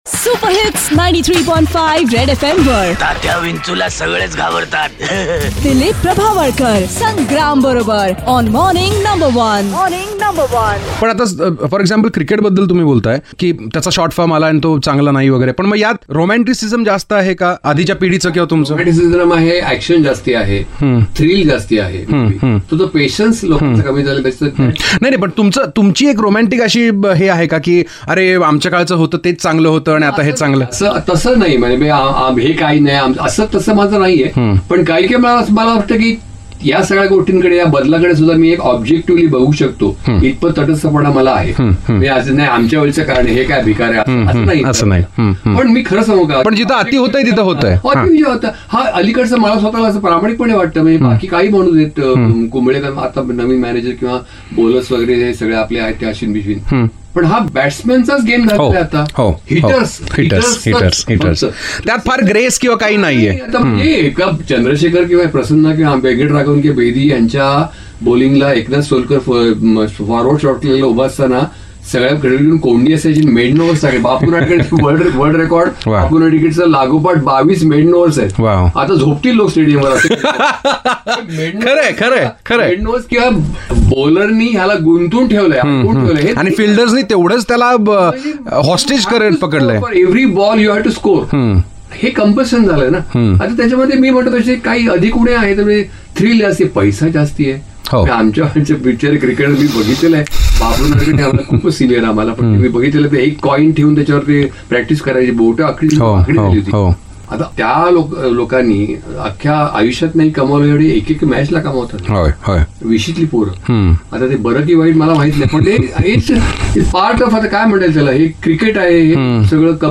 Vetran Marathi Actor Dilip Prabhavalkar in a candid chat